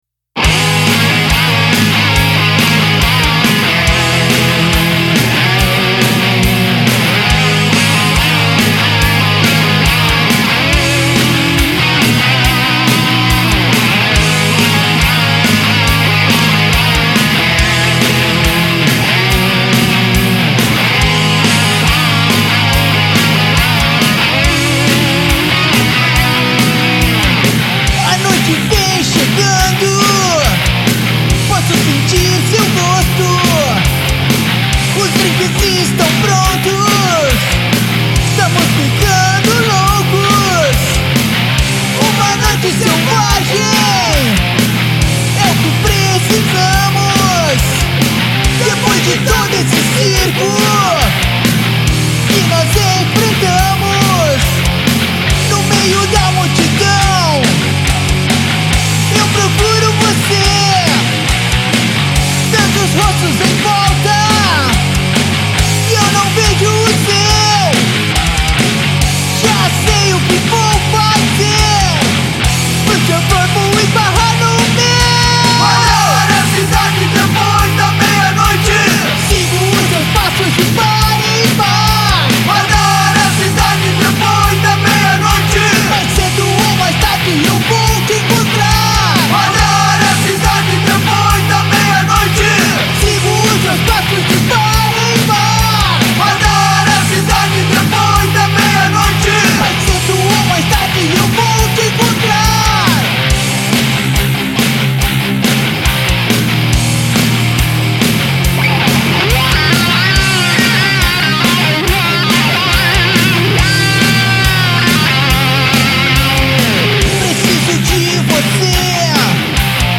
EstiloHard Rock